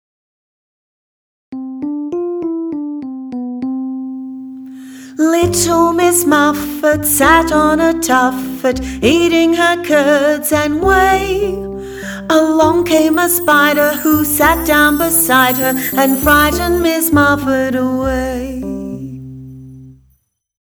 We find that same melody used for Little Miss Muffet.
Little-Miss-Muffet-voc.mp3